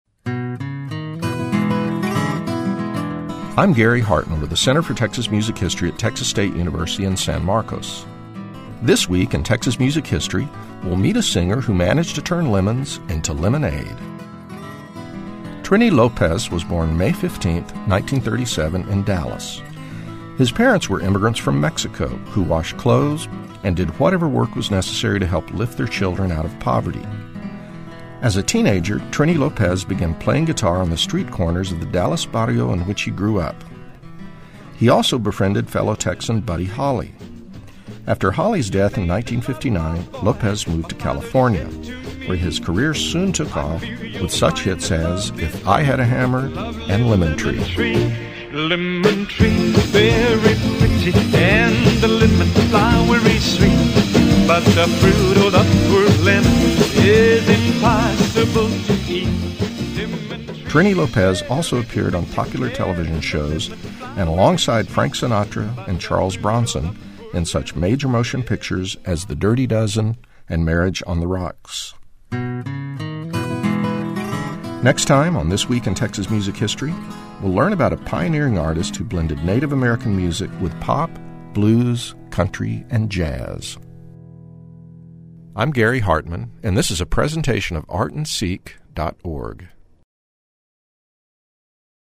This week, Texas music scholar